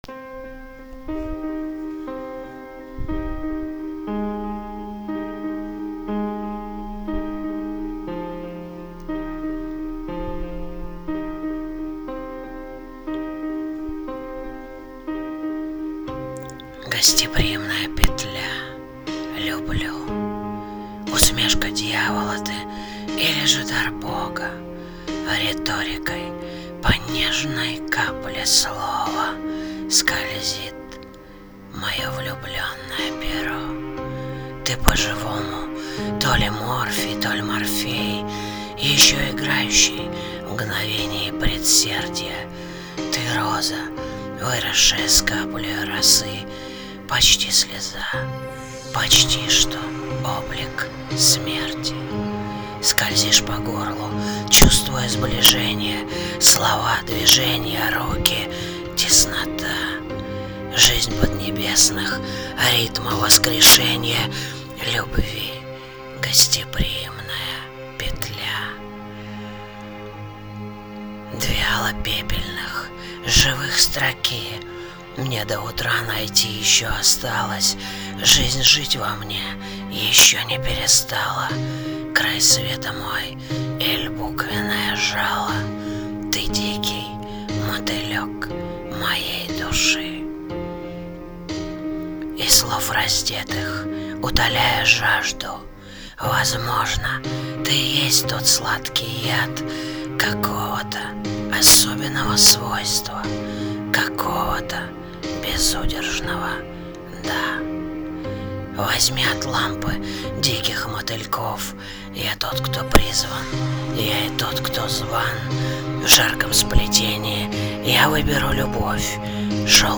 Стихи